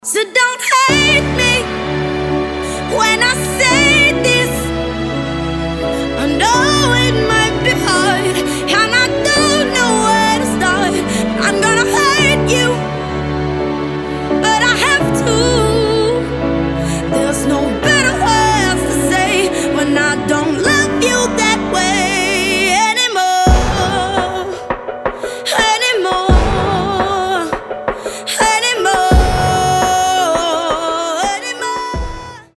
• Качество: 320, Stereo
женский вокал
красивый женский голос
soul